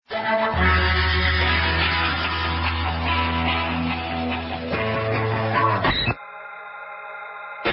Section#1-Sweepers, sound effects
All tracks encoded in mp3 audio lo-fi quality.
classic rock2 sweeper